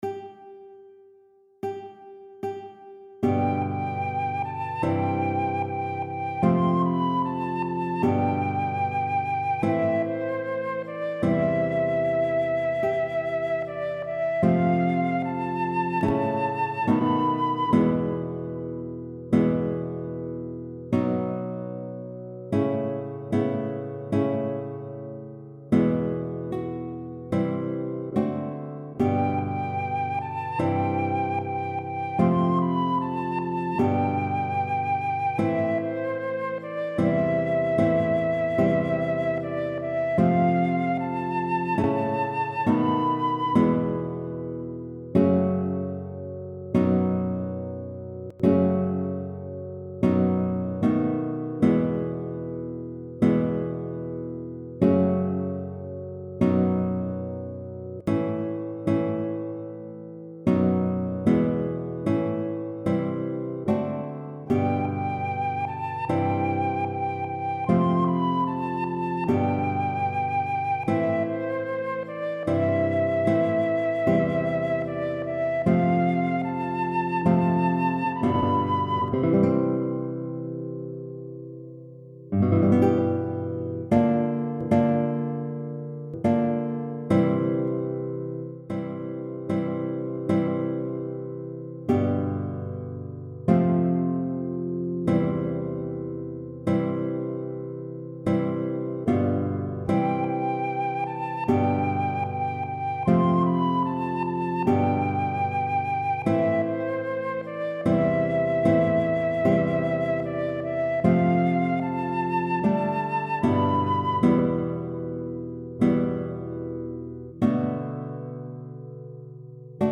Verseless audio with flute on antiphon: